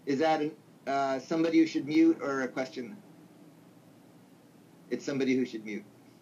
So I compressed some audio (Neil's voice of course) that I clipped from previous weeks of fab academy, you can download the original .m4a files down here: